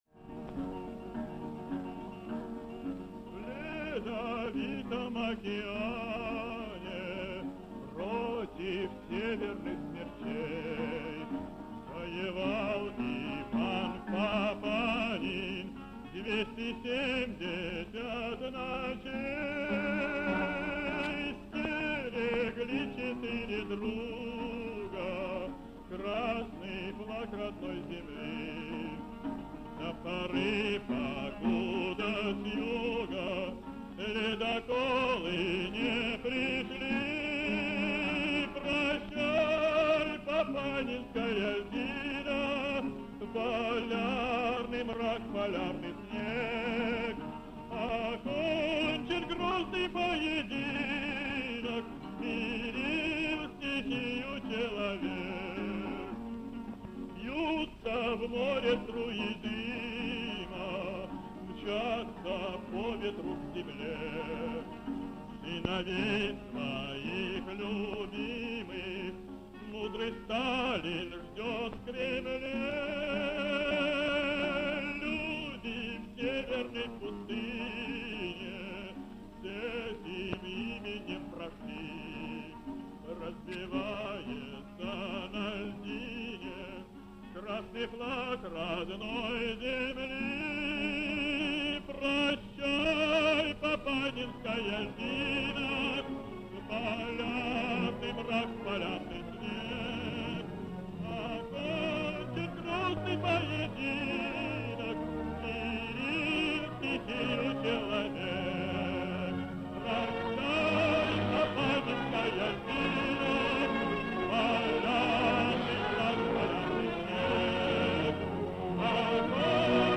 Душевное исполнение песни под аккомпанемент гитары.